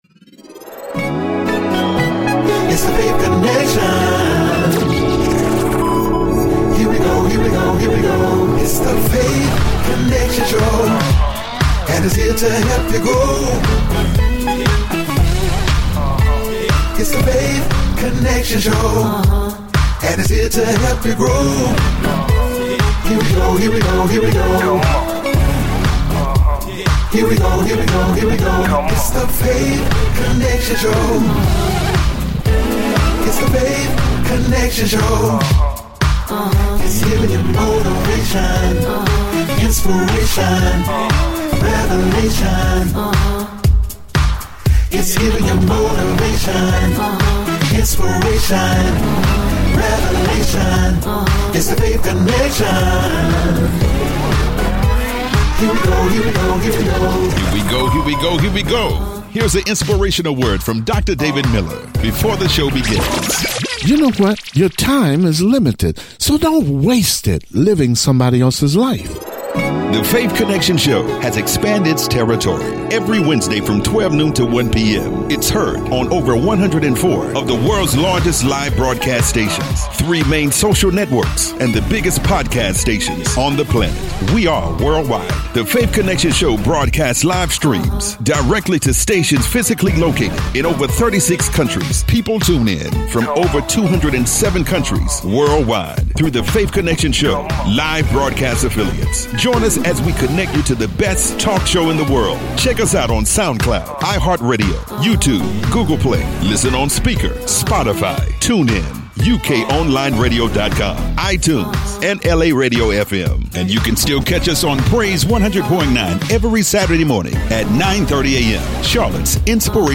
Weekly talk show in which we interview people that have gone through tremendous struggles, have overcome and been able to succeed.